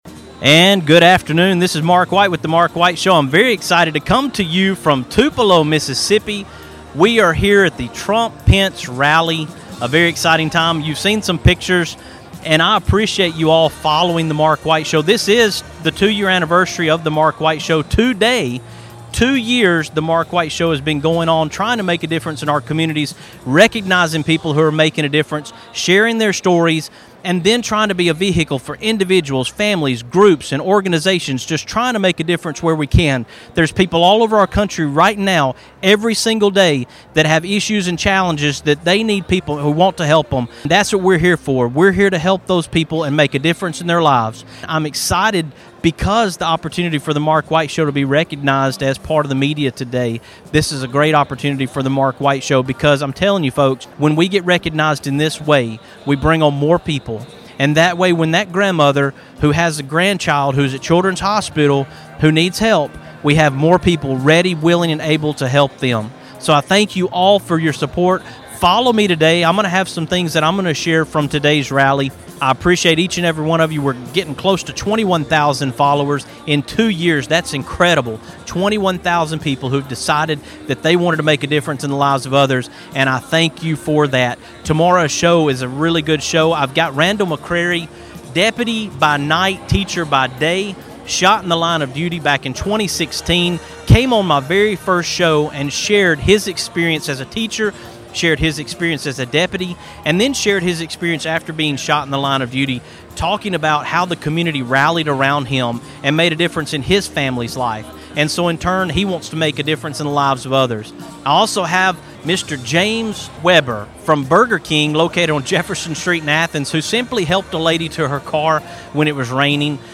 This afternoon, TMWS is in Tupelo, Mississippi, covering President Trump.